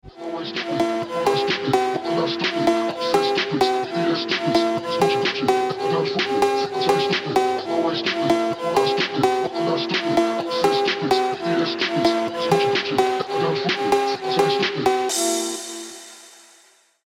Best Ringtones, Phonk Music